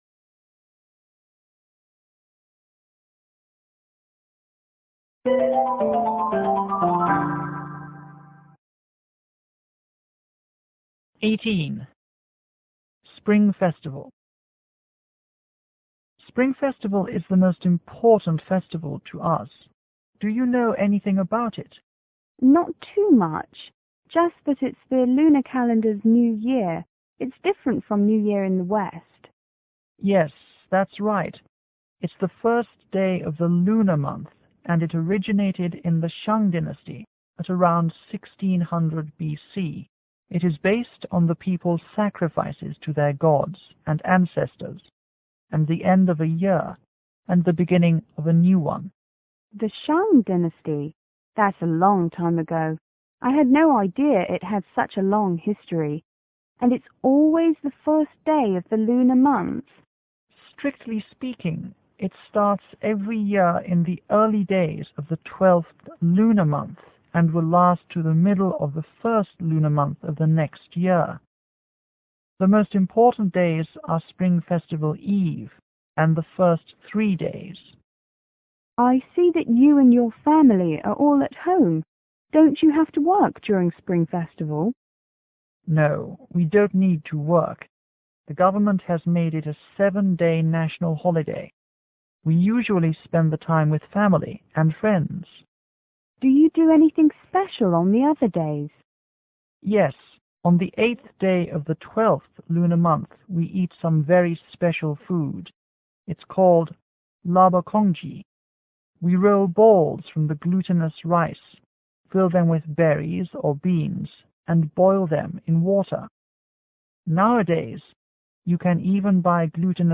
L: Local    V: Visitor